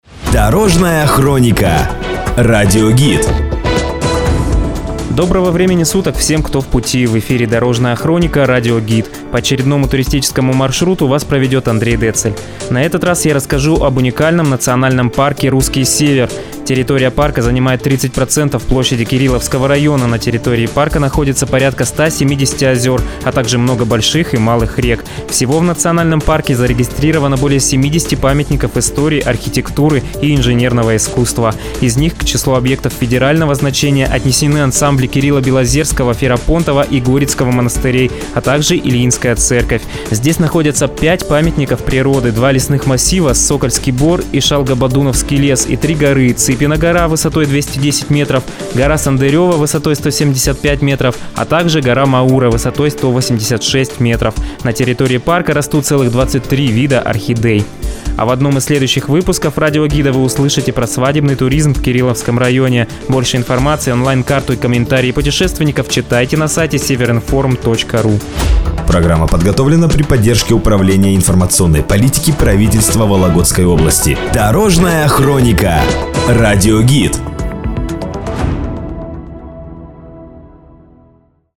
Радиогид. Национальный парк "Русский Север"